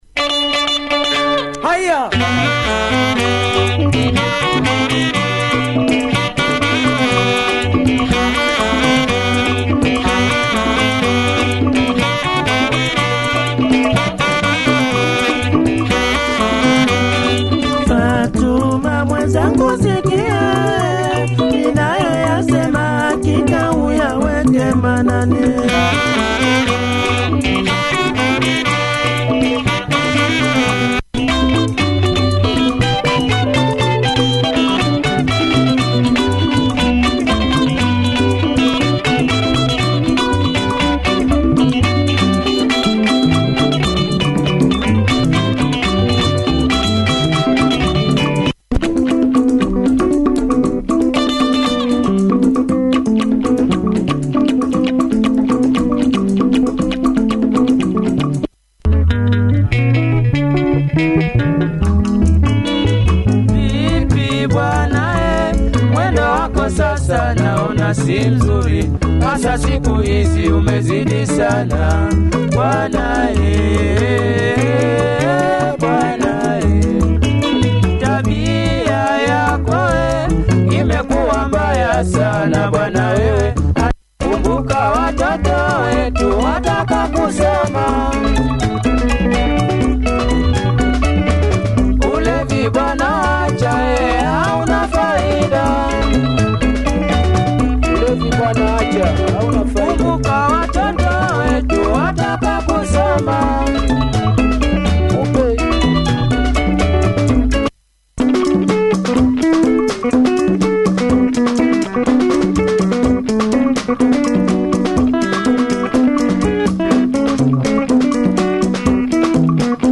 Good sax drive